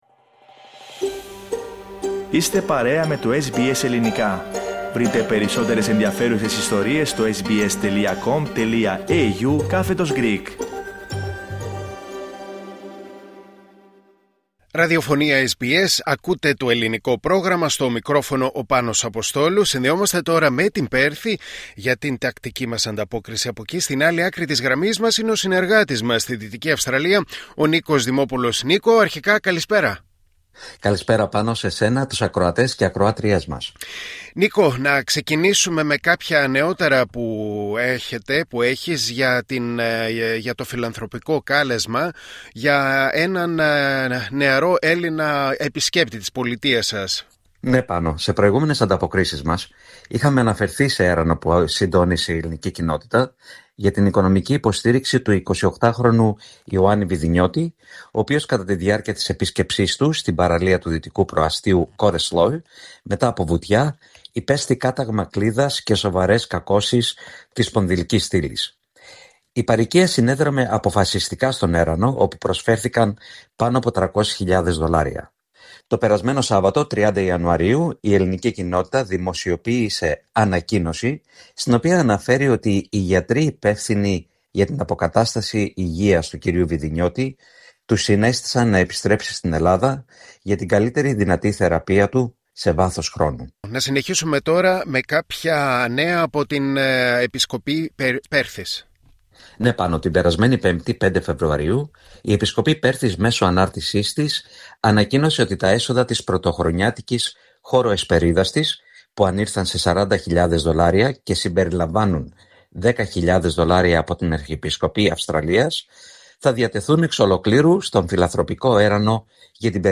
Ακούστε την ανταπόκριση από την Πέρθη